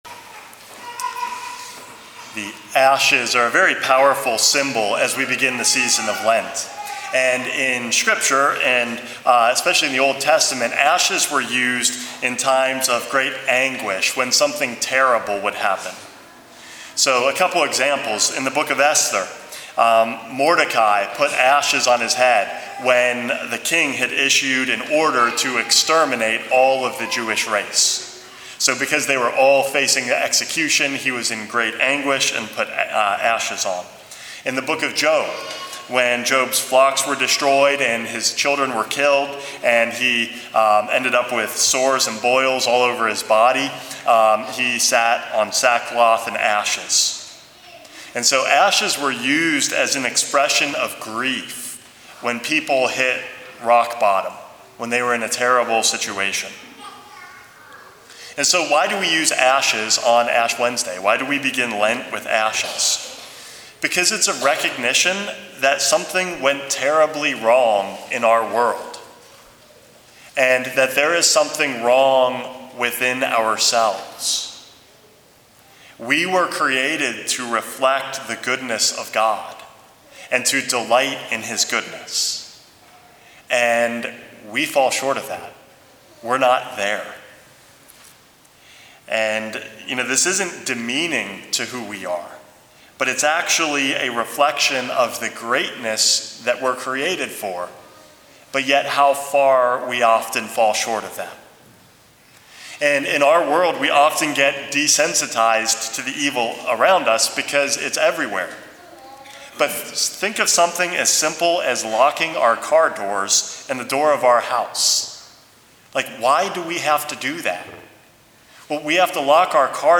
Homily #438 - Internalizing Ashes